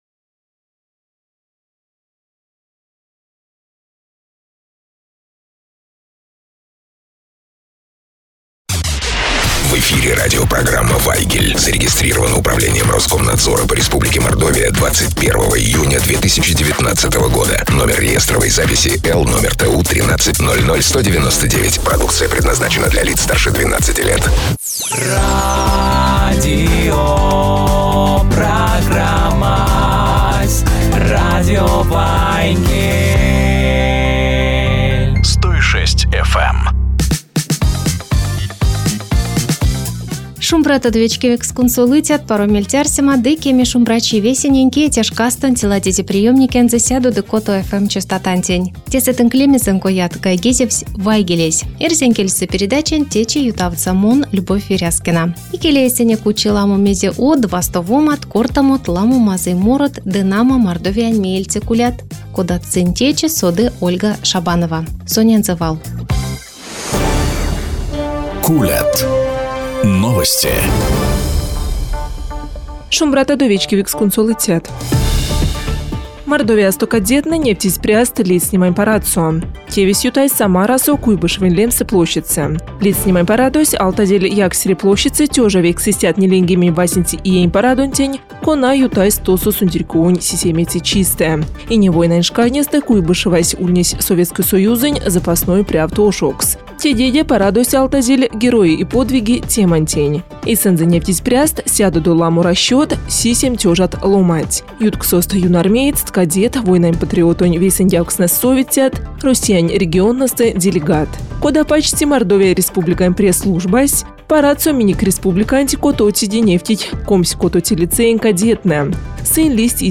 О VIII Спартакиаде пенсионеров России в эфире радио "Вайгель" рассказал участник соревнований